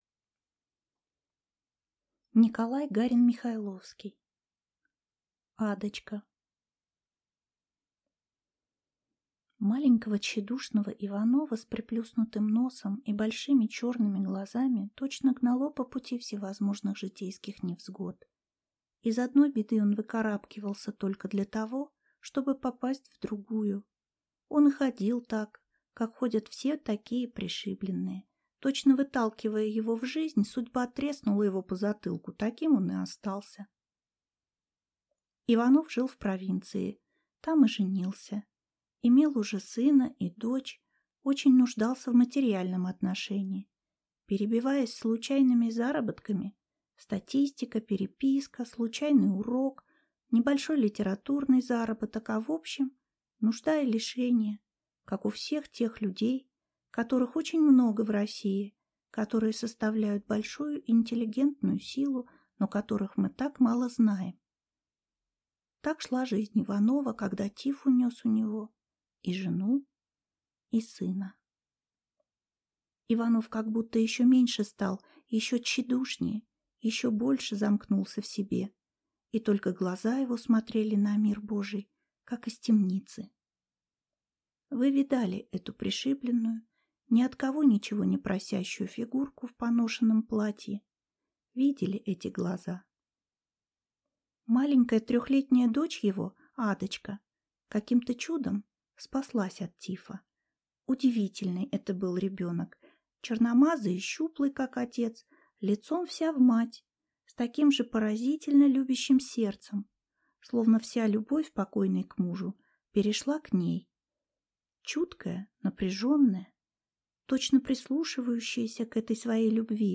Аудиокнига Адочка | Библиотека аудиокниг